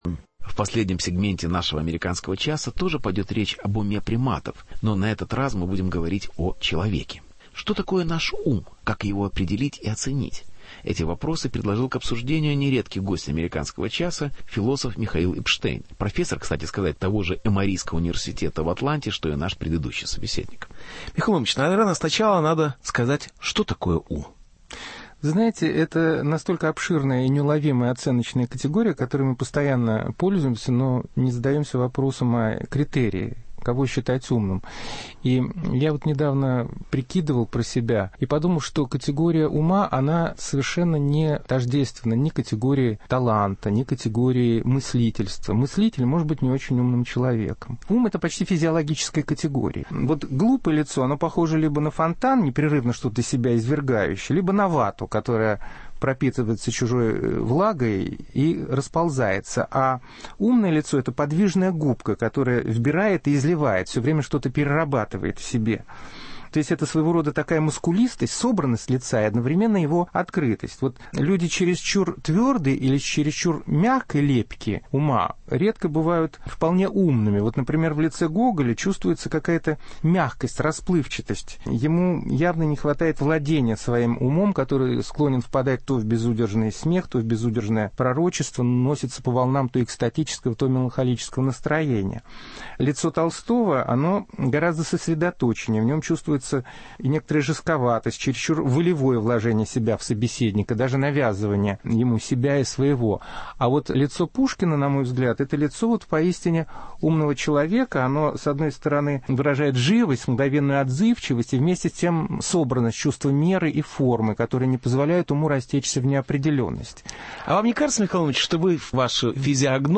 Критерии ума в историко-литературном контексте. Беседа с философом Михаилом Эпштейном